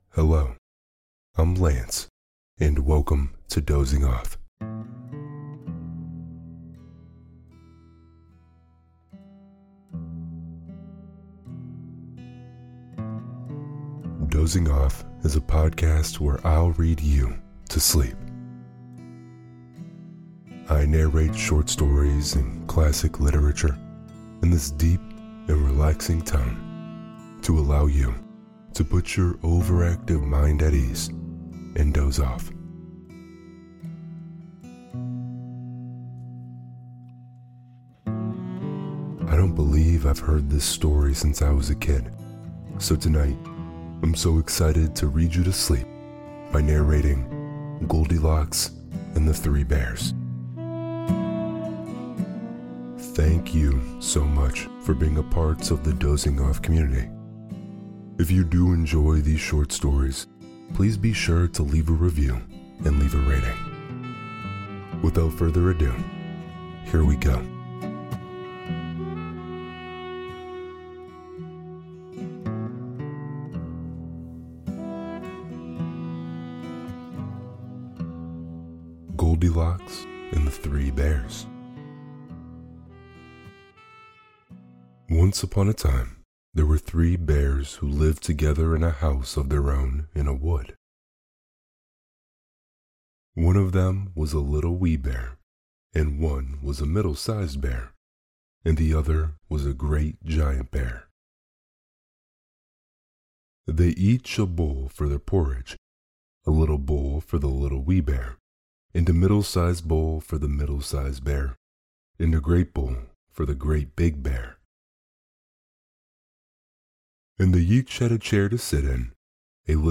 Peter Pan - J.M Barrie (Part Two) – Dozing Off | Deep Voice ASMR Bedtime Stories – Lyssna här